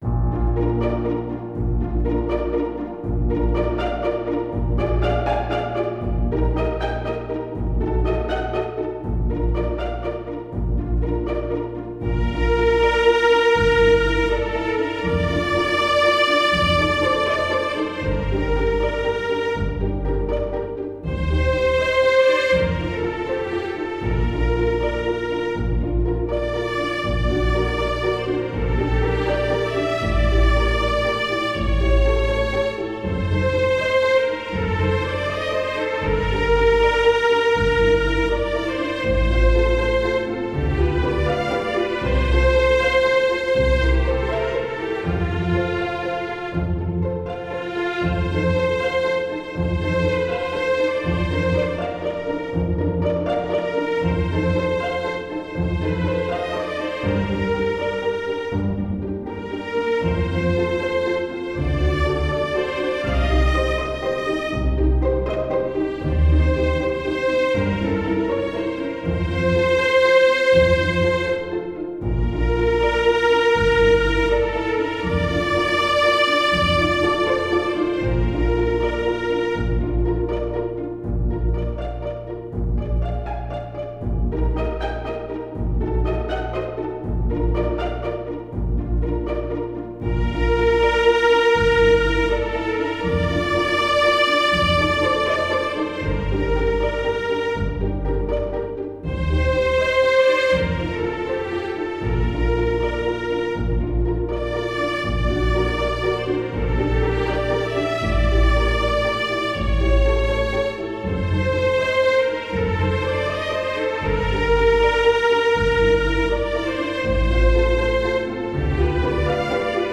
para Orquestra de Cordas e Piano
● Violino I
● Violino II
● Viola
● Violoncelo
● Contrabaixo